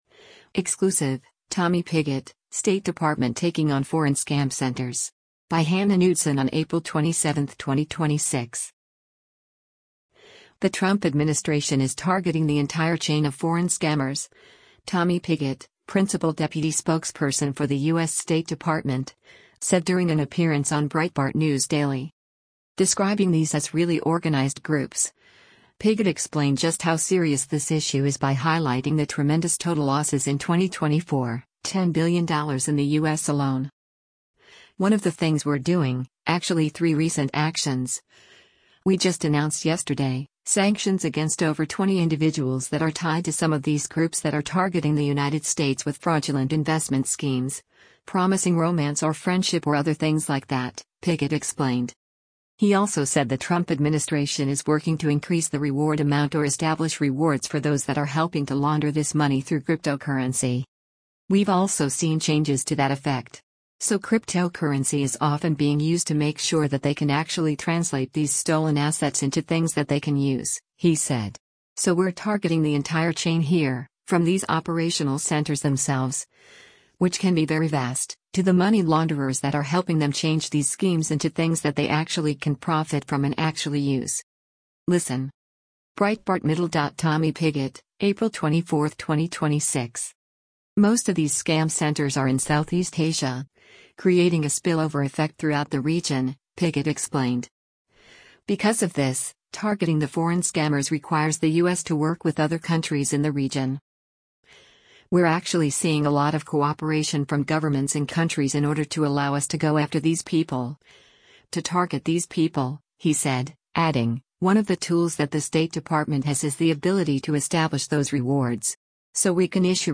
The Trump administration is targeting the “entire chain” of foreign scammers, Tommy Pigott, principal deputy spokesperson for the U.S. State Department, said during an appearance on Breitbart News Daily.
Breitbart News Daily airs on SiriusXM Patriot 125 from 6:00 a.m. to 9:00 a.m. Eastern.